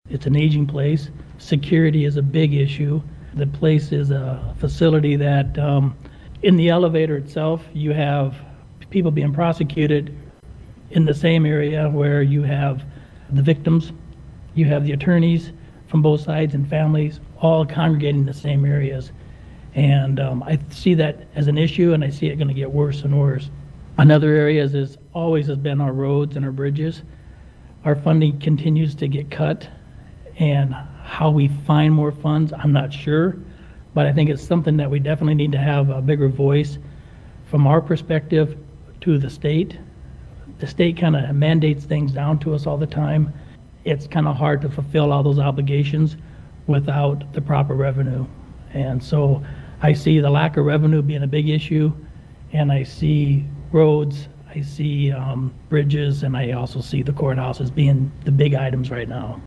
During a candidate forum hosted by the League of Women Voters Pierre and Dakota Radio Group, the candidates agreed lack of revenue, roads and bridges and upgrades needing to be done in the courthouse in Pierre are three major issues for Hughes County right now.